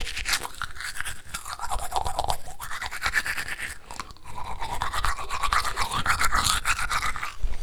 Cepillándose los dientes
Me gusta Descripción Grabación sonora del sonido producido por una persona al cepillarse los dientes. Se aprecia el sonido del cepillo frotando contra los dientes en la cabidad bucal